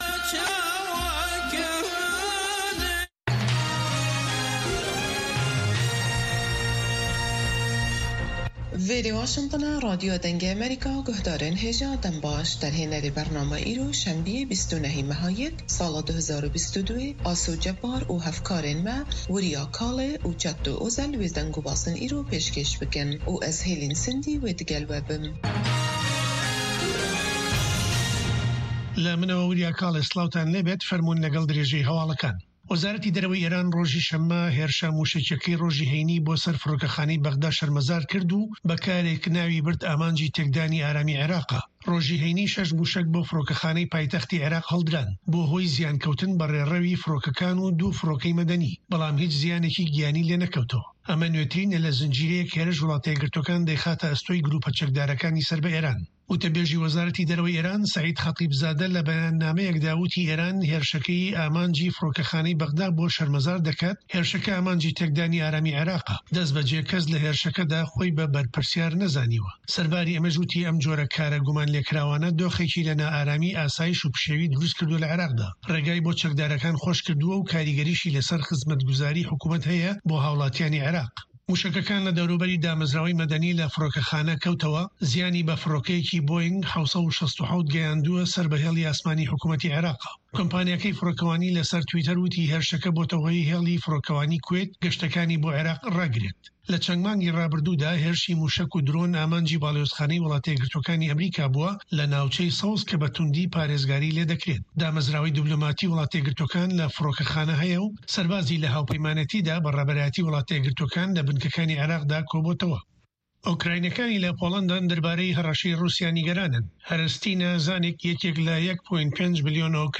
هه‌واڵه‌کان ، ڕاپـۆرت، وتووێژ.